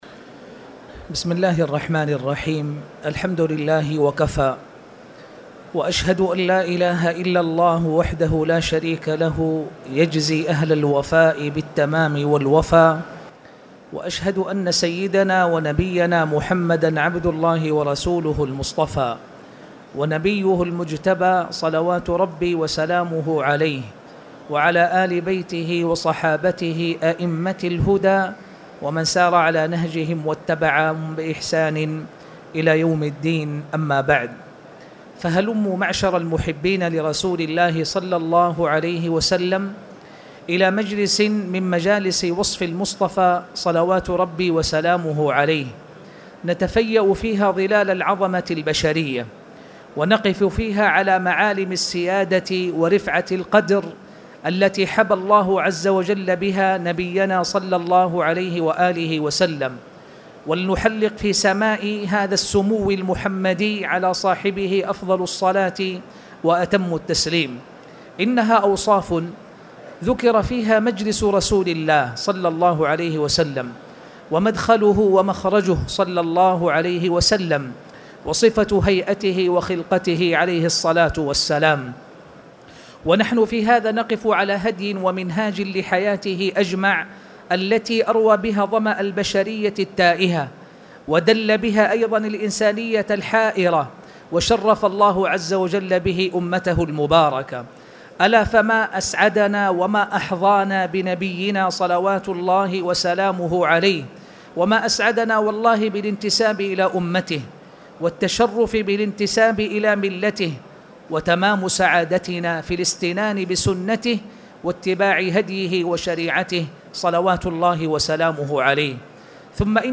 تاريخ النشر ١٦ ربيع الأول ١٤٣٨ هـ المكان: المسجد الحرام الشيخ